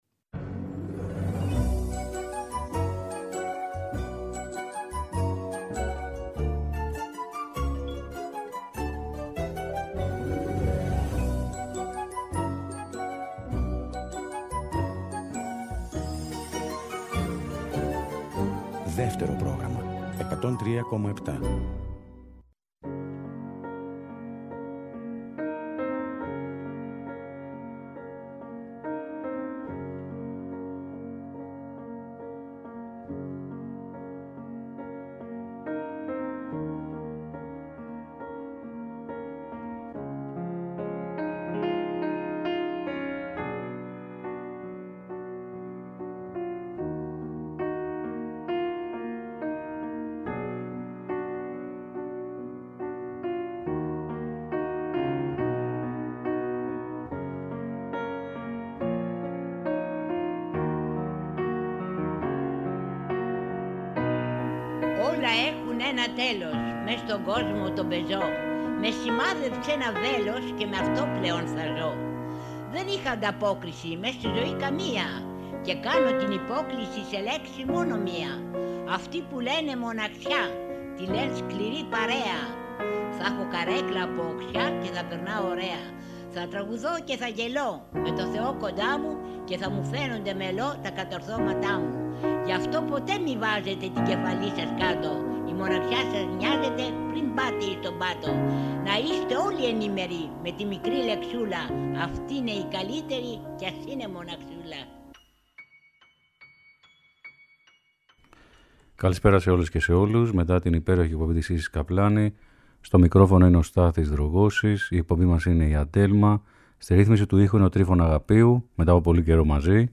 τιμά την ελληνική folk.
Τραγούδια τρυφερά παραδοσιακά ψυχεδελικά και ελληνικά